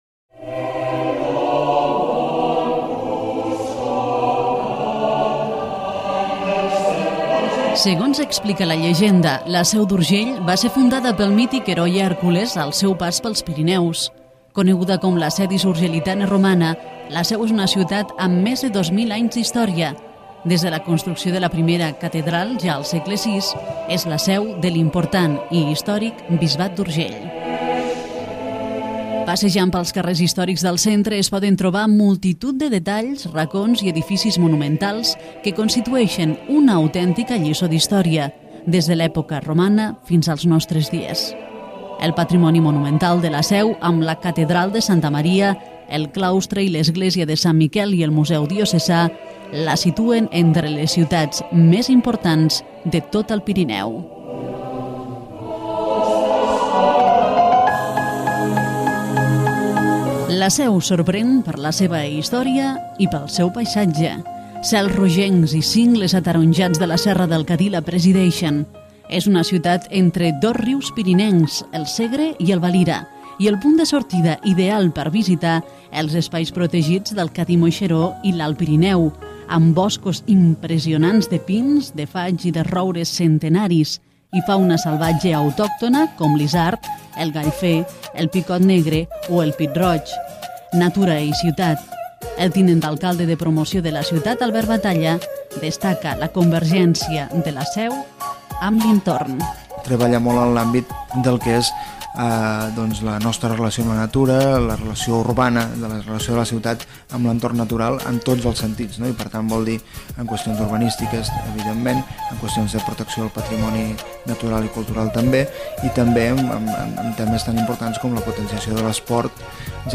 Història de La Seu d'Urgell, declaracions d'Albert Batalla, activitats a la ciutat